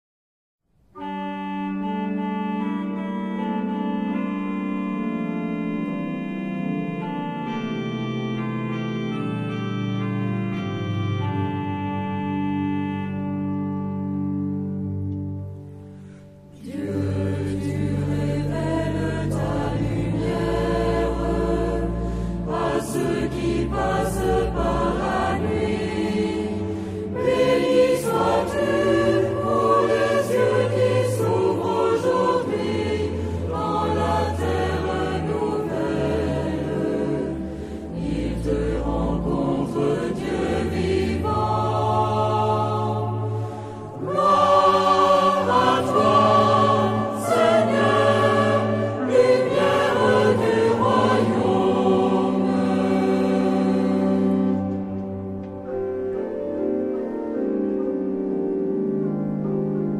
Género/Estilo/Forma: Sagrado ; Himno (sagrado)
Carácter de la pieza : calma
Tipo de formación coral: SATB  (4 voces Coro mixto )
Tonalidad : dorico